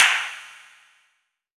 clapGood.wav